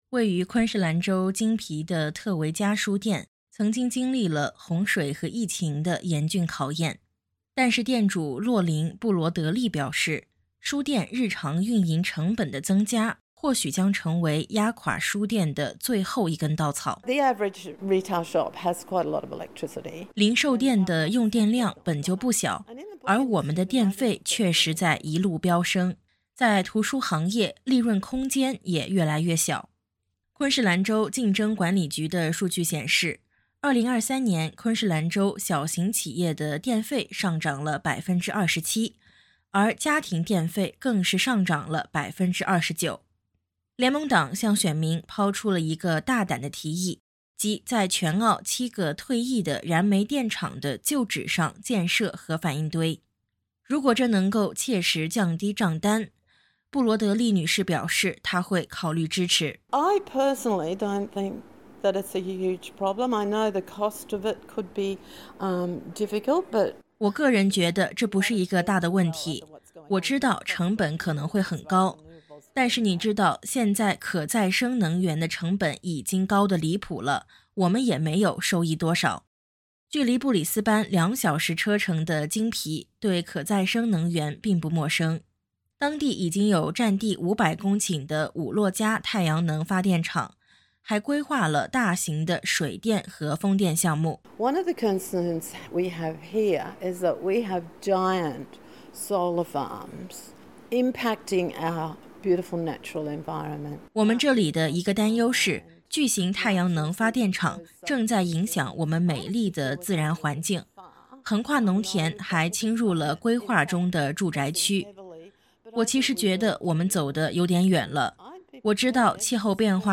联盟党提出了将核能纳入国家能源组合的计划，包括在昆士兰州偏远地区建造两个核反应堆。SBS记者前往昆士兰偏远地区，探访当地居民如何看待联盟党的这一核能计划。点击音频，收听完整报道。